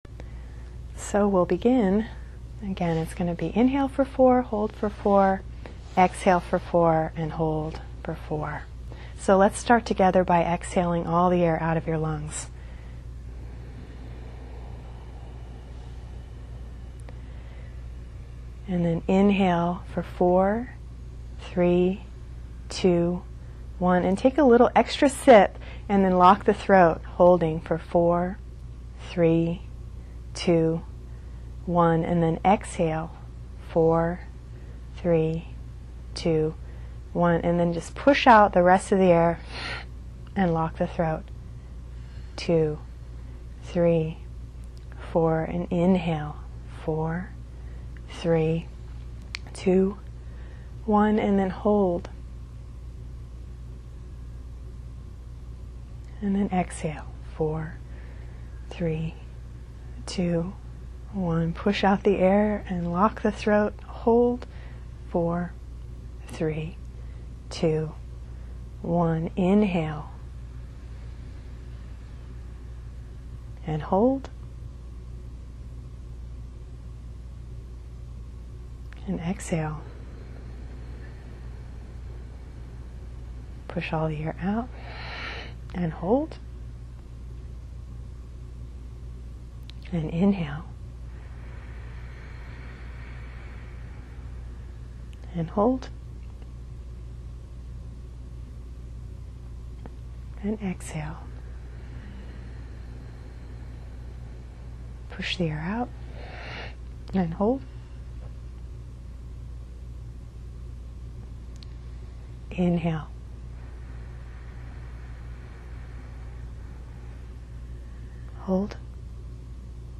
Breathing Exercise